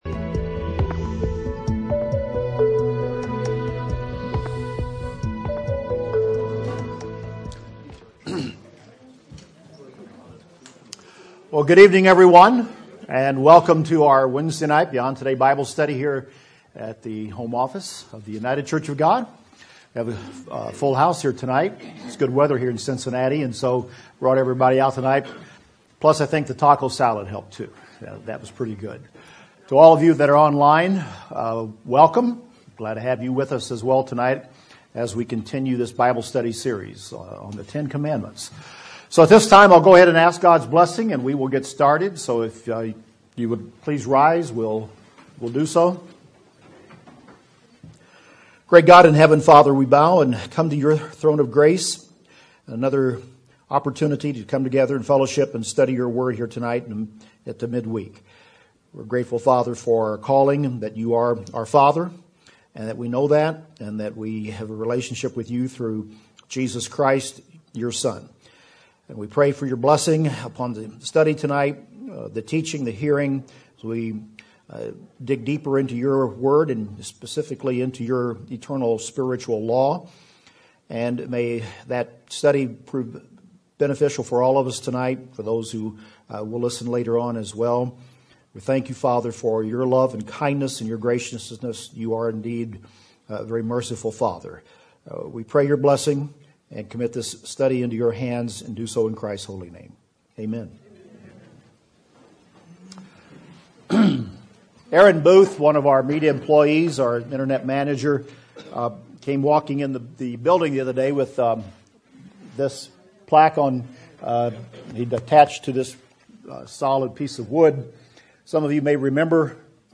This is the third part in the Beyond Today Bible study series: The Ten Commandments.